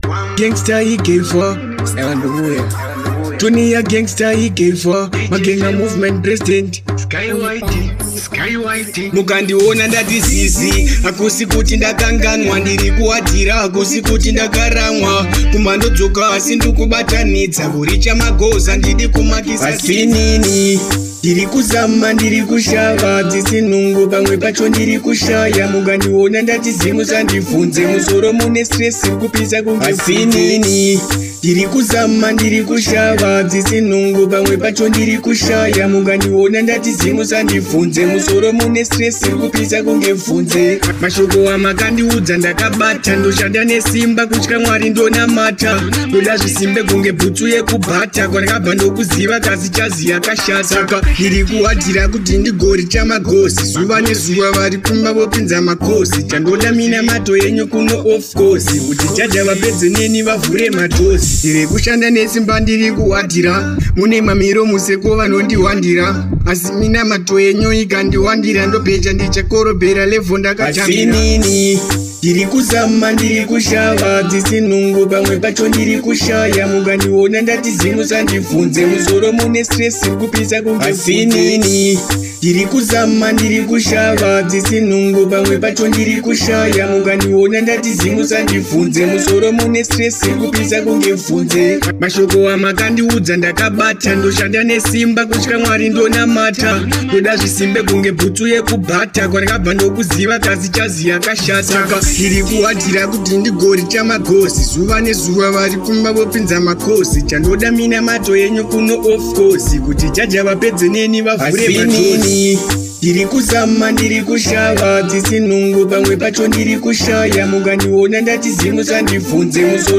• Style: Dancehall